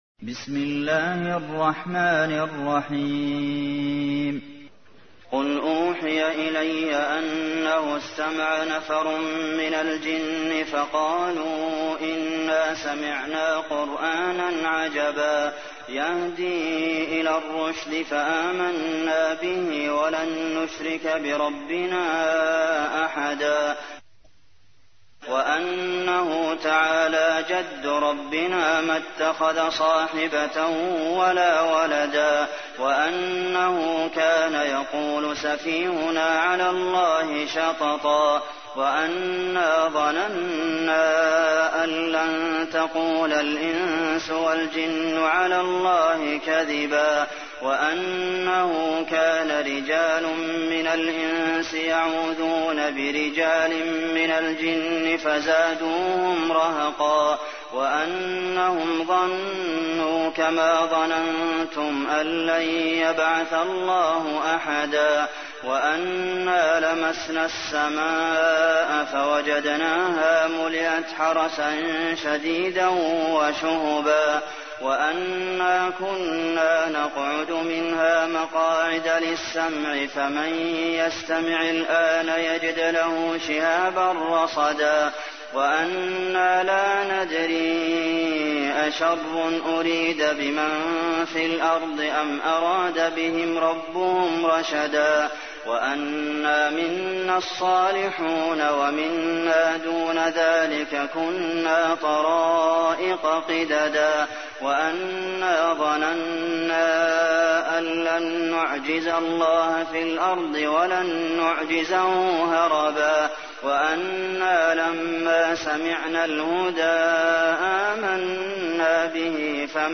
تحميل : 72. سورة الجن / القارئ عبد المحسن قاسم / القرآن الكريم / موقع يا حسين